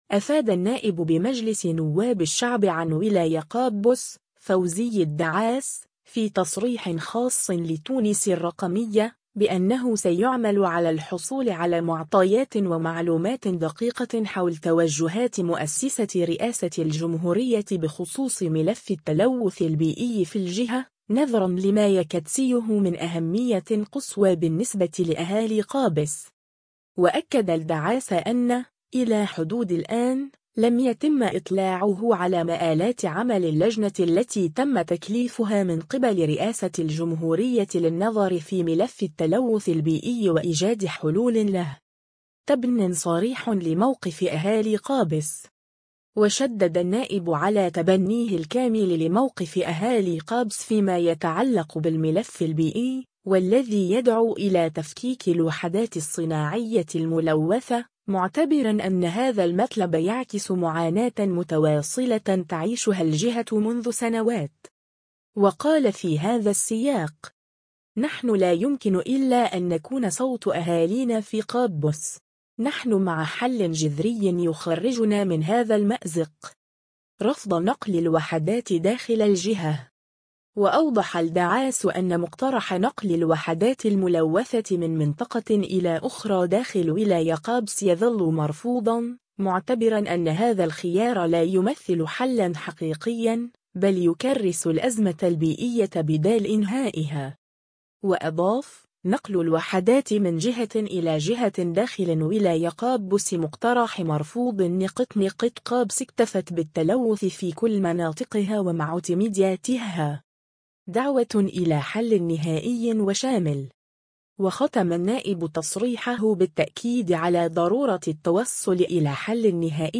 أفاد النائب بمجلس نواب الشعب عن ولاية قابس، فوزي الدعاس، في تصريح خاص لـ”تونس الرقمية”، بأنه سيعمل على الحصول على معطيات ومعلومات دقيقة حول توجهات مؤسسة رئاسة الجمهورية بخصوص ملف التلوث البيئي في الجهة، نظرًا لما يكتسيه من أهمية قصوى بالنسبة لأهالي قابس.